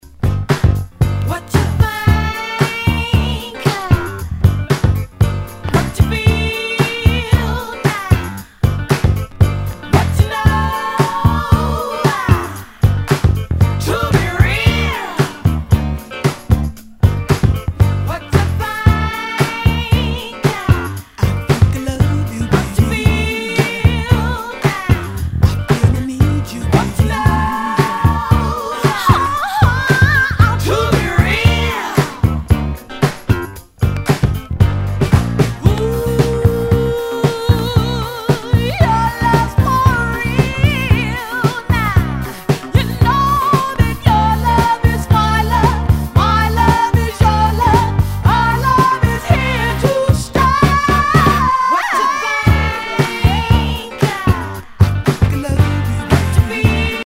SOUL/FUNK/DISCO
ナイス！ダンス・クラシック！